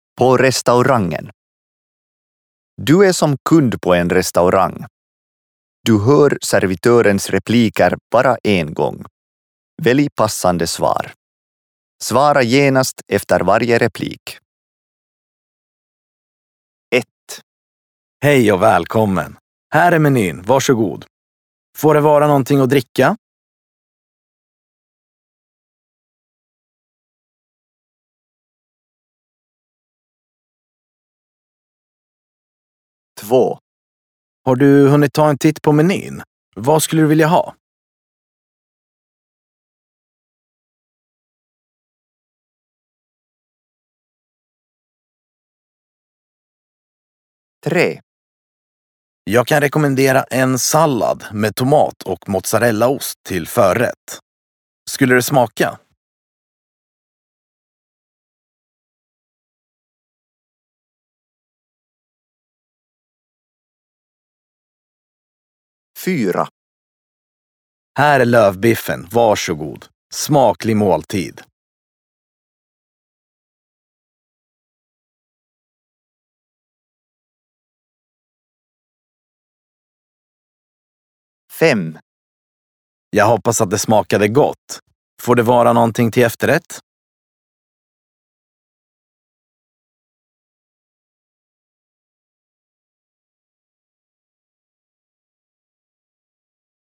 Olet asiakkaana ravintolassa. Kuulet tarjoilijan repliikit vain kerran. Valitse sopivin vastaus kunkin repliikin jälkeen.